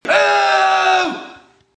Category: Television   Right: Personal
Tags: Jeapordy Game Show Sounds Effect